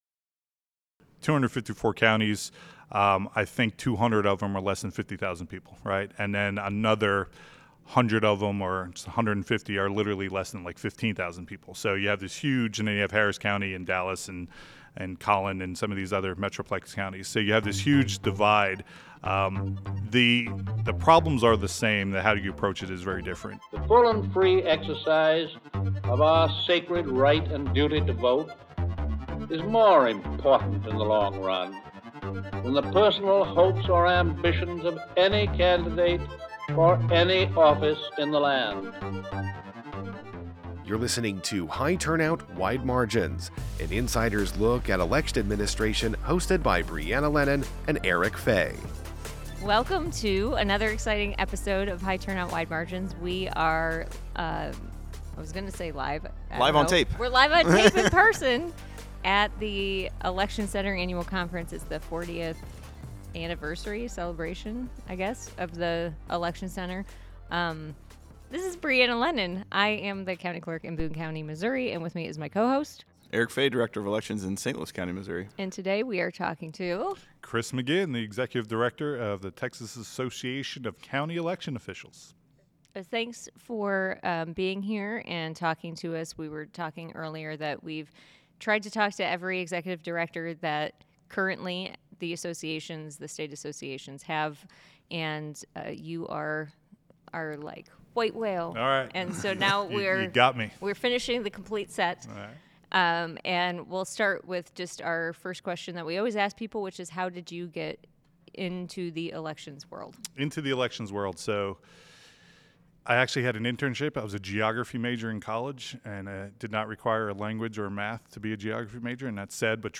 High Turnout Wide Margins recently traveled to the 40th annual Election Center conference in Salt Lake City, Utah, and spoke with election administrations and officials from across the county about how they do the work of elections in their communities.